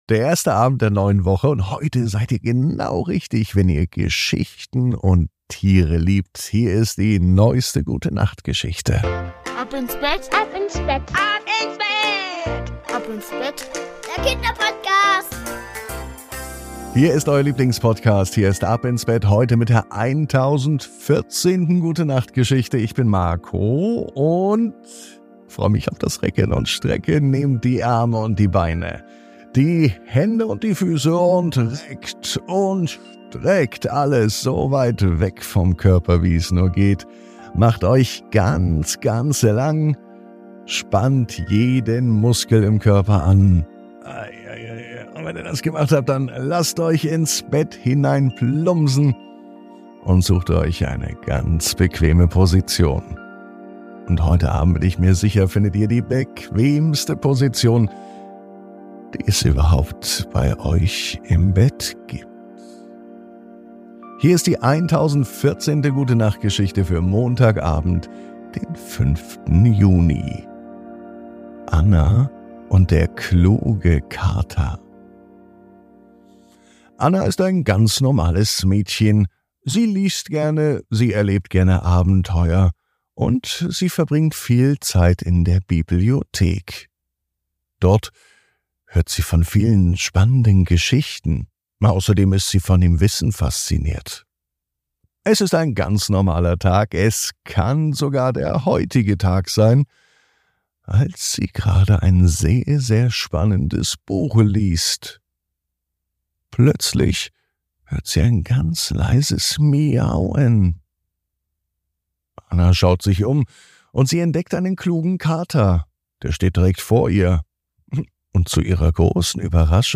Die Gute Nacht Geschichte für Montag